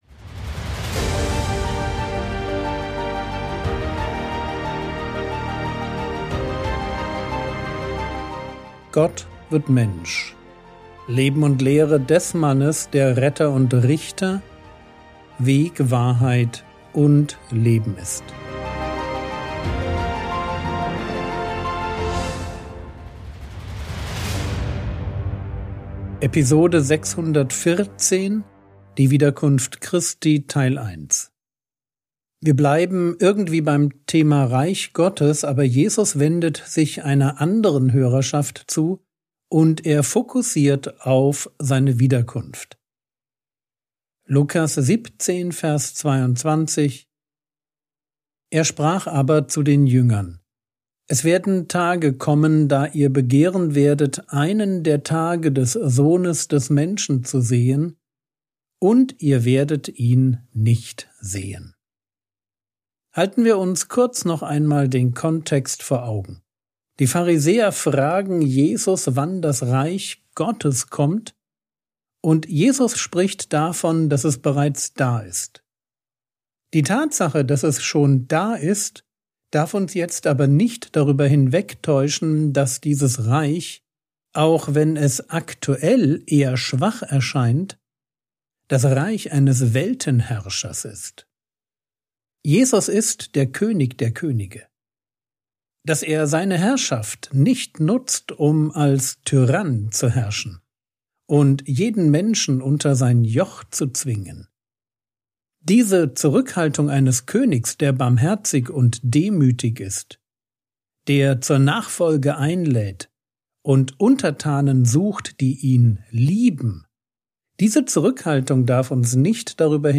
Frogwords Mini-Predigt Podcast - Episode 614 | Jesu Leben und Lehre | Free Listening on Podbean App